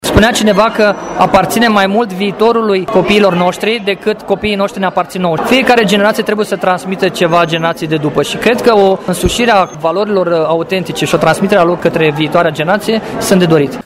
În urma acestui curs, judecătorul Dănileț speră că dintre cei care au participat, se vor alege viitorii voluntari, care să susțină cursuri de educație juridică elevilor într-un mod nonformal și interactiv: